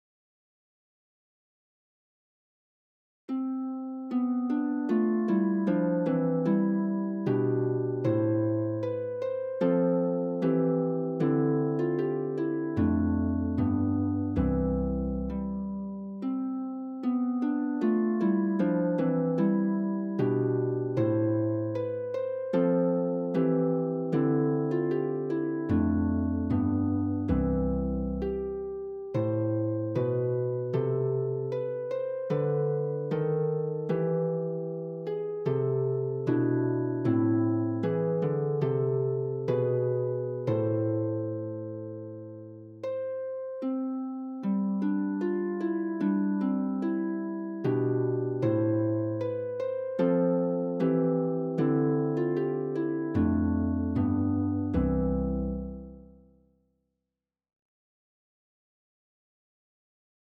The Minstrel Boy | Free Easy Celtic Harp Sheet Music
Free printable sheet music for The Minstrel Boy by Thomas Moore for Easy Harp Solo.
minstrel_boy_harp.mp3